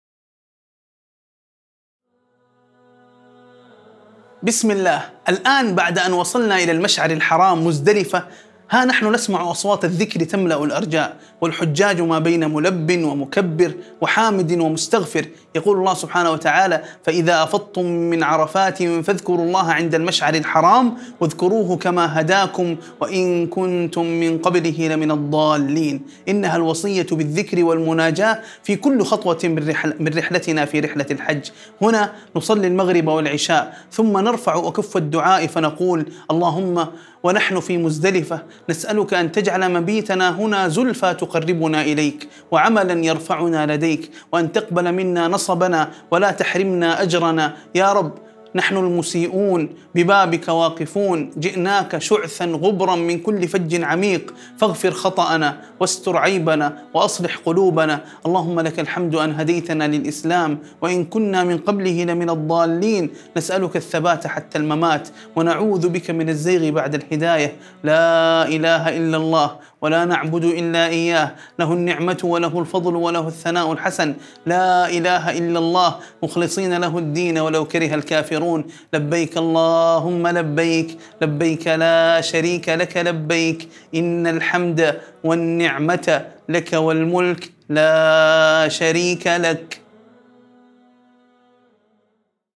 مناجاة ودعاء مؤثر من مشعر مزدلفة في الحج، يفيض بالتضرع والخشوع وطلب القرب من الله والمغفرة. يعكس النص جوّ الإيمان والأنس بالله في هذا الموقف العظيم، مع التلبية والثناء على النعم.